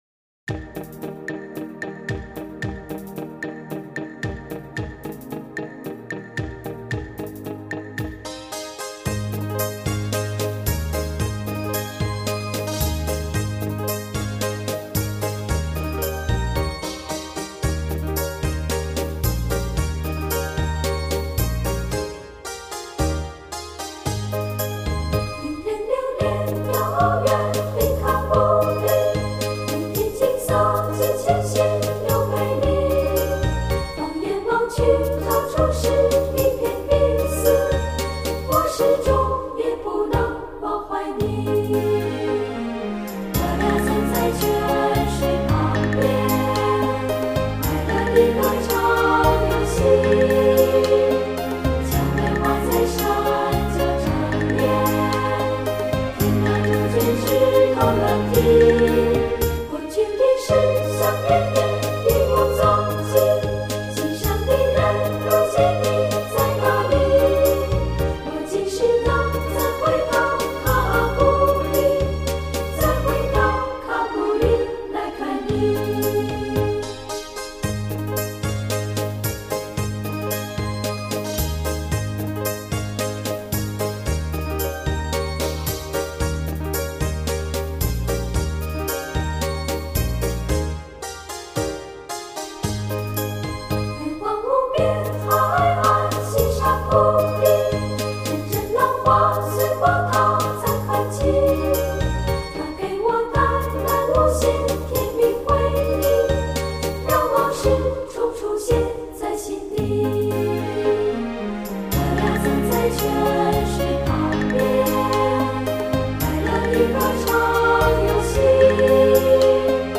童声合唱是一种广受欢迎的形式，它包含错落的声部构成
与和谐悠扬的齐唱。